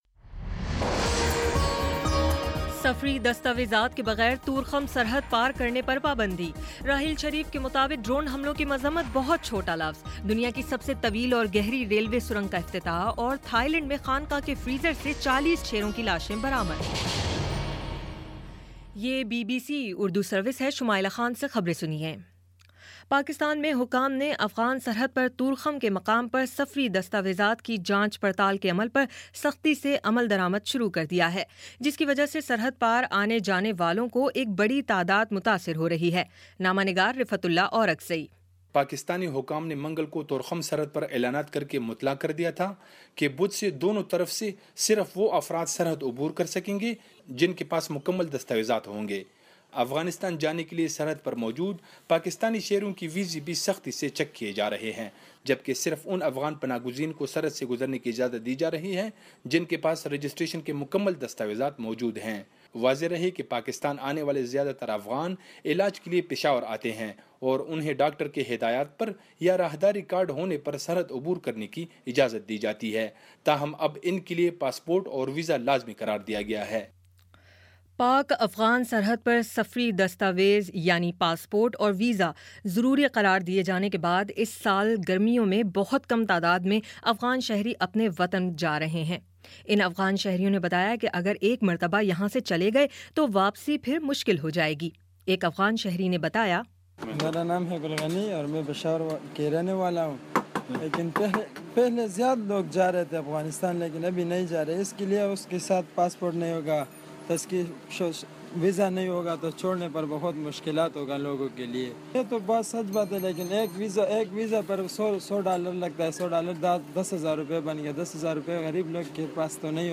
جون 1: شام پانچ بجے کا نیوز بُلیٹن